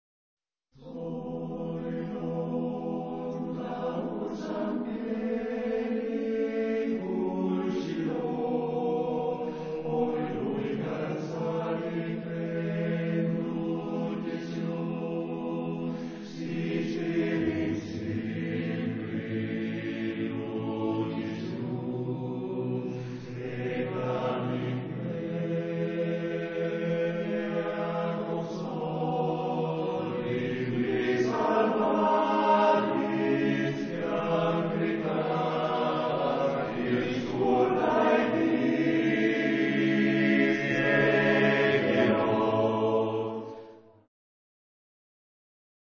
REPERTORIO DEL CORO ANA AVIANO